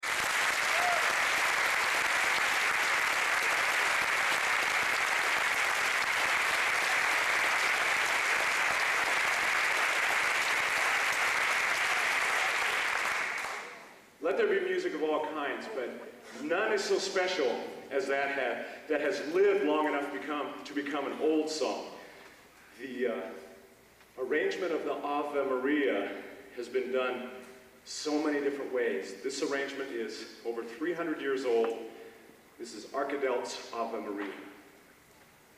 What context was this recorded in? Location: Purdue Memorial Union, West Lafayette, Indiana